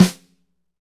Index of /90_sSampleCDs/Northstar - Drumscapes Roland/DRM_Pop_Country/SNR_P_C Snares x